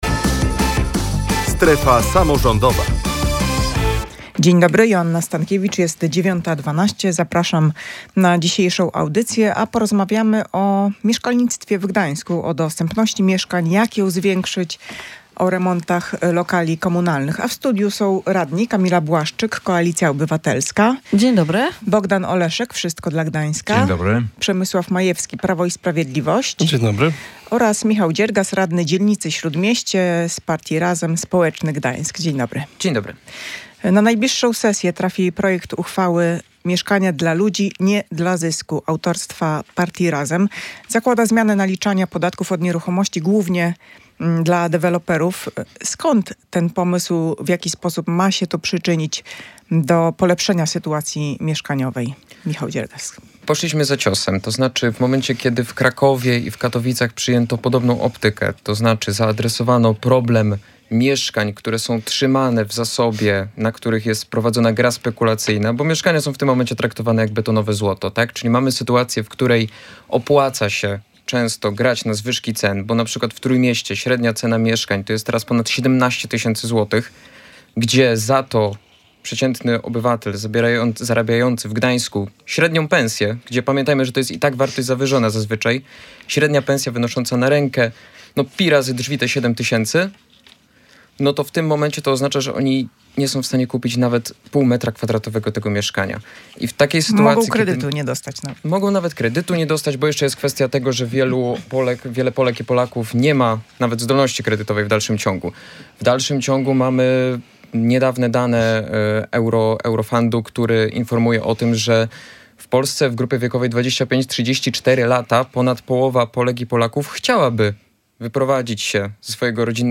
Mieszkania dla ludzi, nie dla zysków? Posłuchaj dyskusji radnych z Gdańska